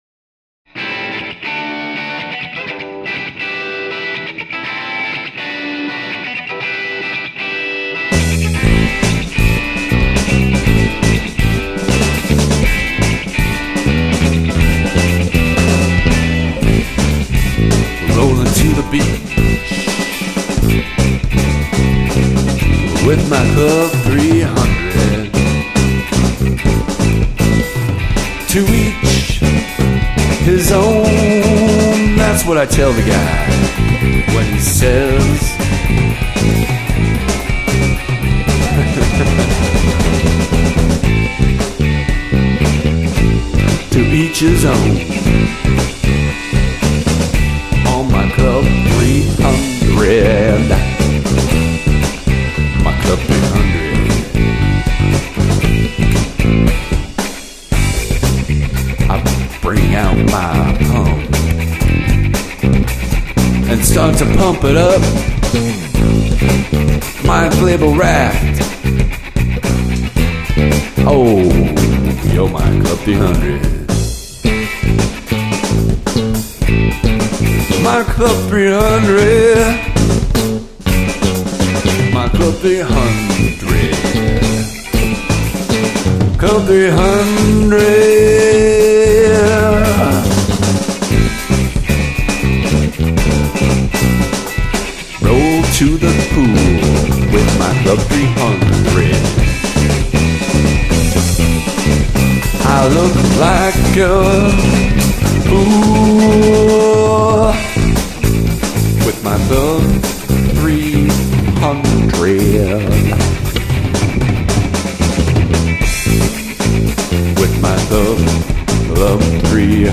Funk Rock
Main Vocals
Bass Guitar
Electric Guitar
Drums
Guitar & bass are awesome :)